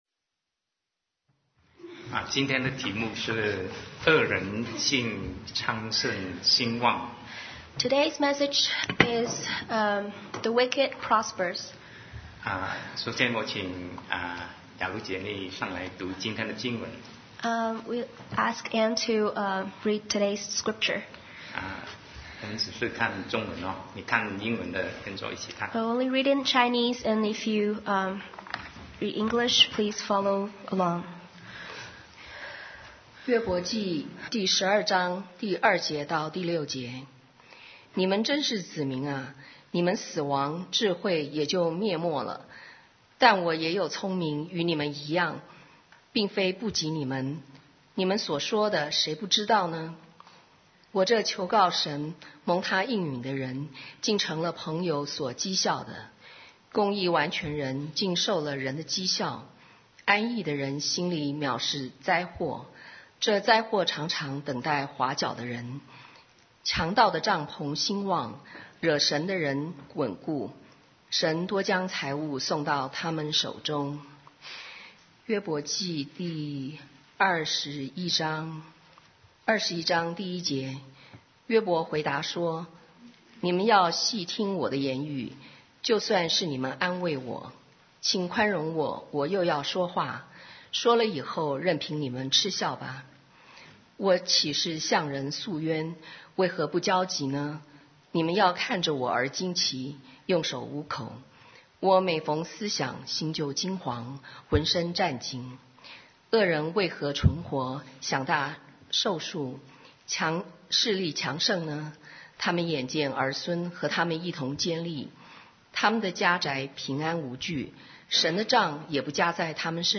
Sermon 2020-02-09 The Wicked Prospers